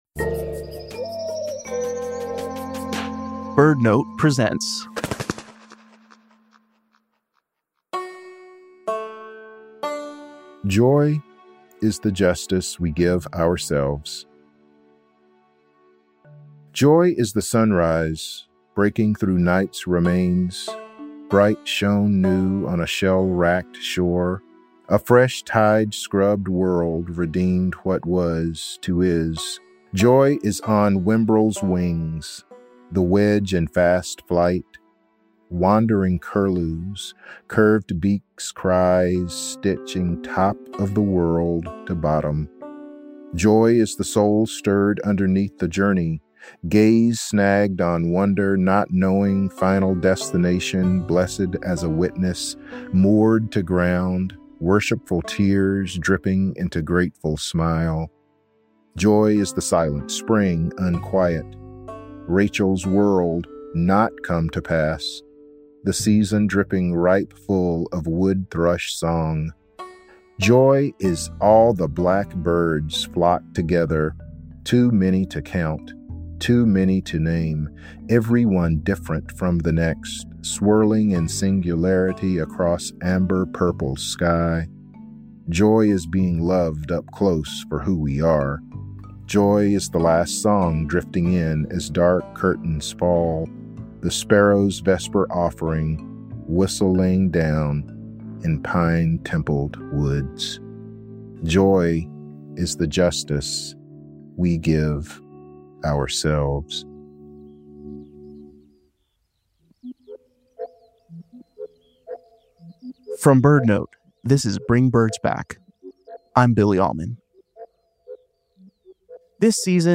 Ornithologist, poet and MacArthur Fellow, Dr. J. Drew Lanham, speaks